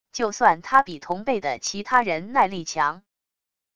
就算他比同辈的其他人耐力强wav音频生成系统WAV Audio Player